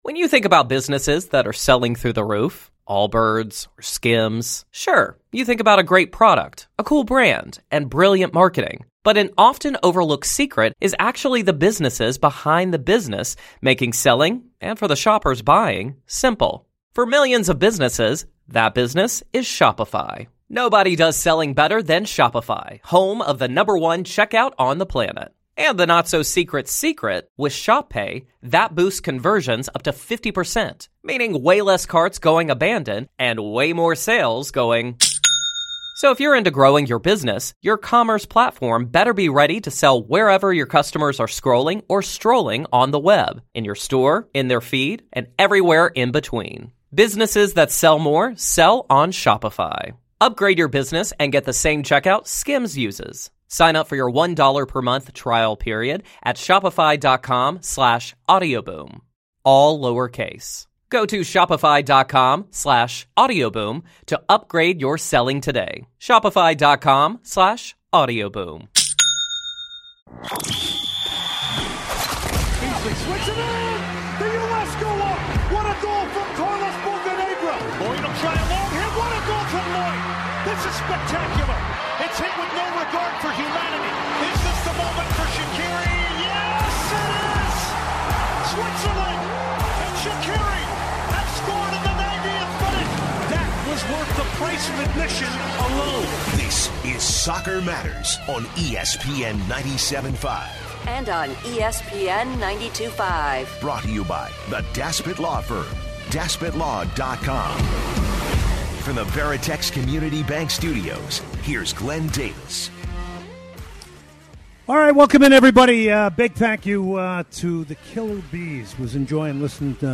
joins the show for an interview regarding MLS, Austin FC, & much more.